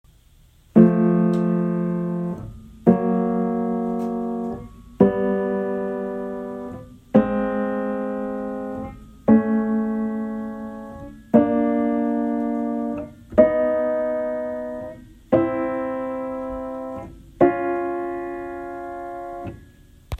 This is a free analysis generated by a recording of M3’s and P4’s submitted to me by one of my subscribers.
M3, unfiltered:
3. Listen to the unfiltered recordings and try to hear the specific beating partial within the unfiltered recording.